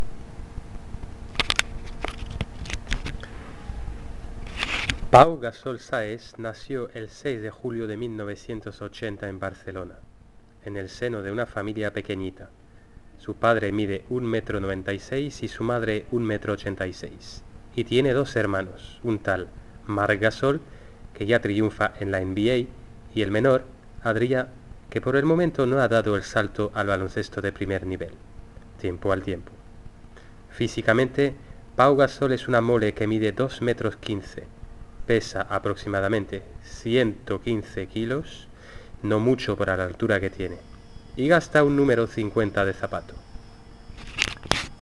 Écoutez la lecture du professeur: